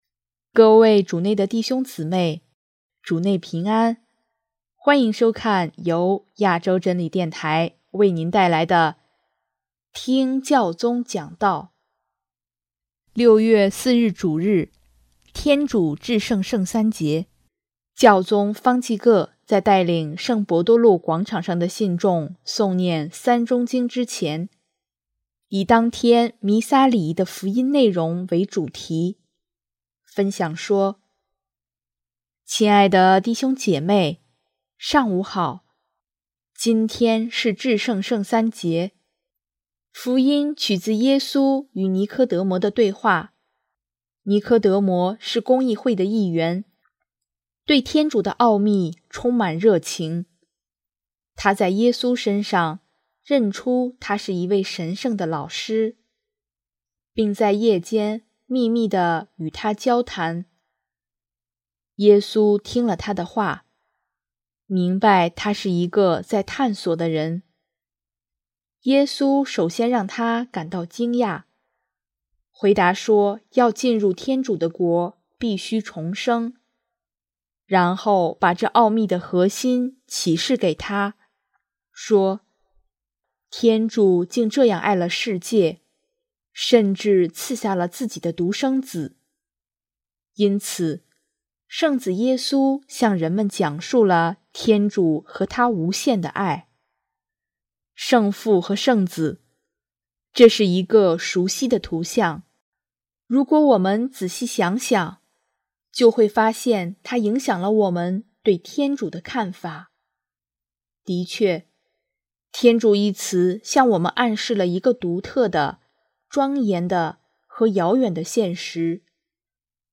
6月4 日主日，天主至圣圣三节，教宗方济各在带领圣伯多禄广场上的信众诵念《三钟经》之前，以当天弥撒礼仪的福音内容为主题，分享说：